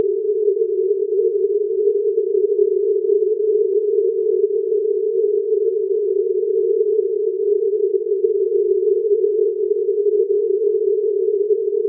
Test signals A1 and A2 use in-phase probe tones of 400 Hz frequency with probe levels of 9 dB and 6 dB below the level of the masker, respectively.
The masker noise is presented in-phase in all cases, despite the sometimes startling spatial effects arising from masking release.